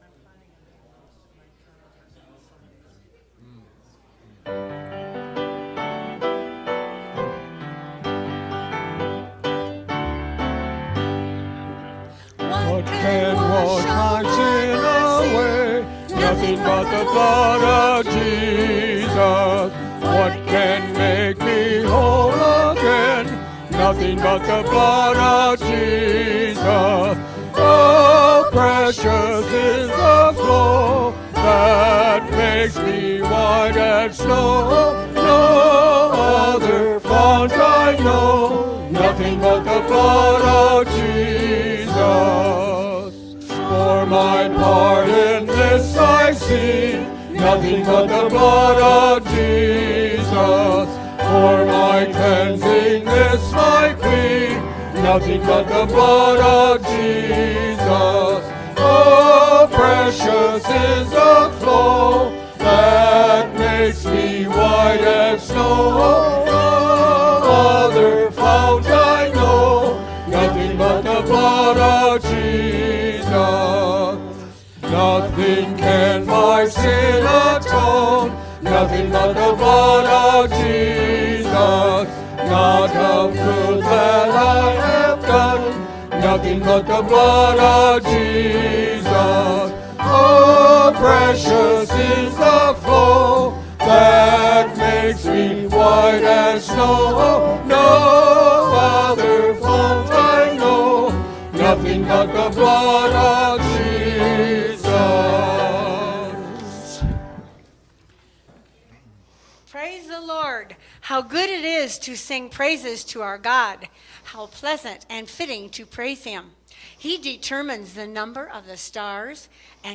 Worship_-February-4_-2024_-voice-only-Trim.mp3